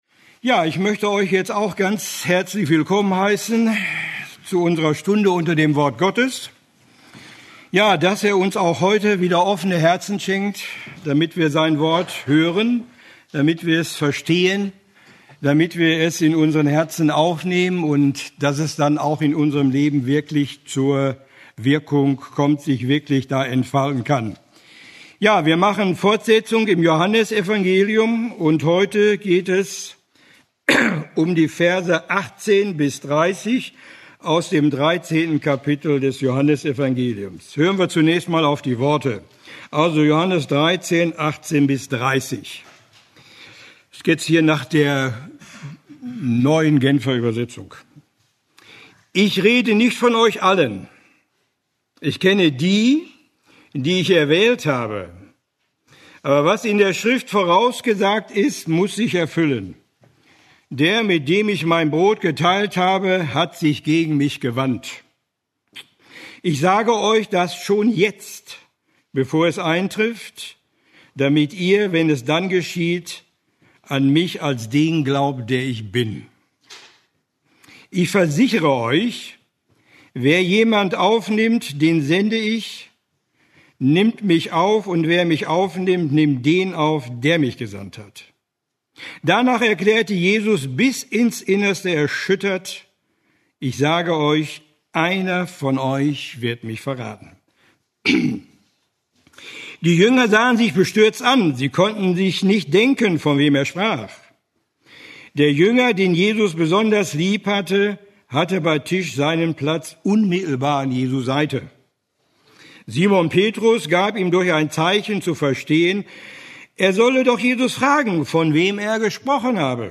Eine predigt aus der serie "Johannes Evangelium."